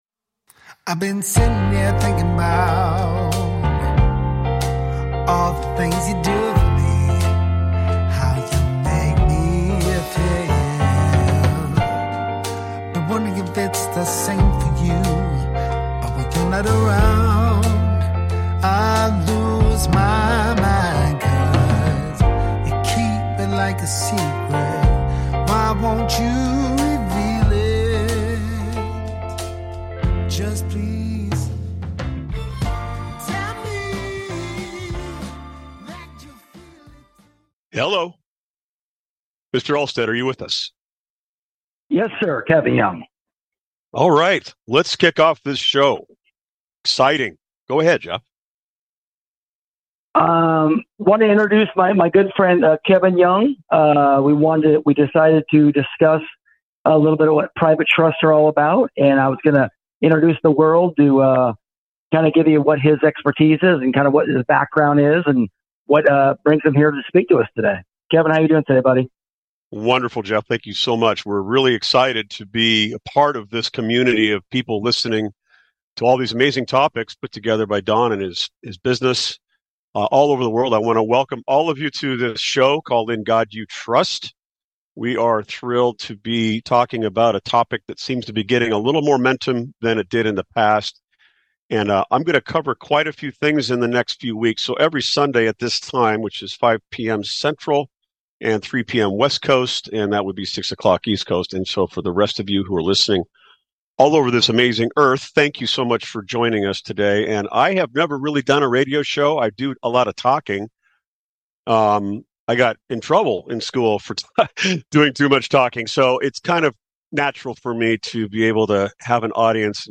In God You Trust Talk Show
Audience Engagement: The show will feature engaging discussions and allow listeners to call in with questions.